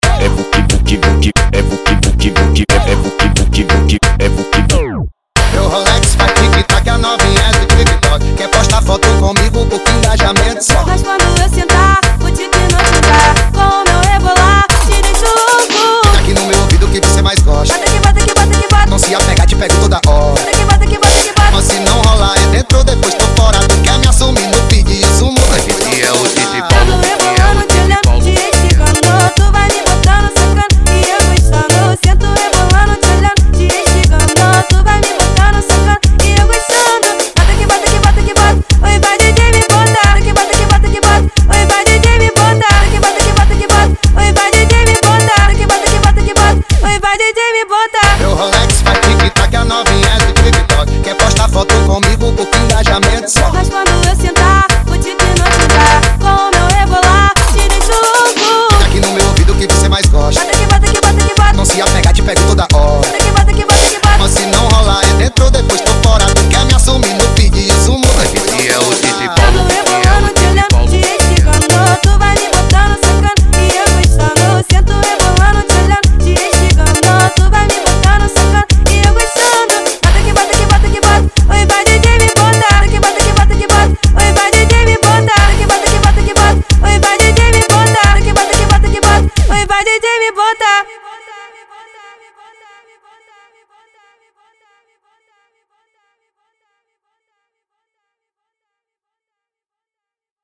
Tecnofunk